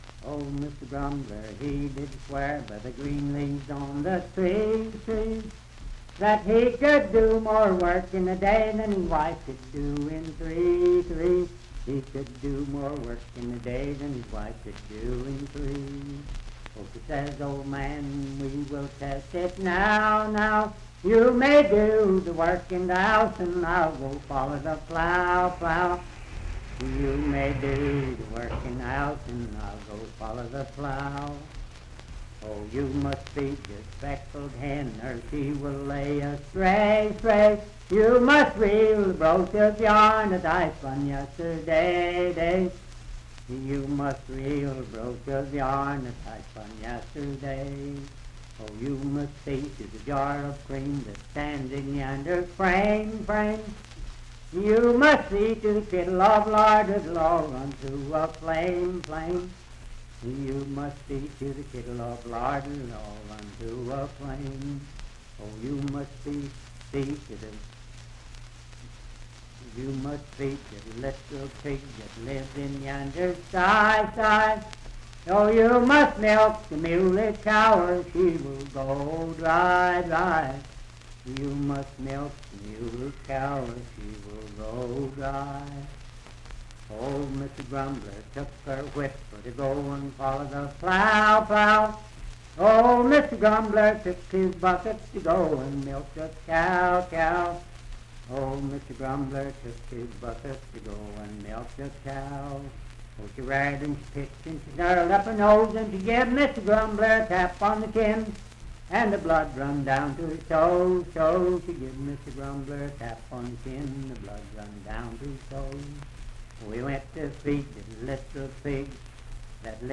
Unaccompanied vocal music and folktales
Verse-refrain 12(6w/R).
Voice (sung)
Wood County (W. Va.), Parkersburg (W. Va.)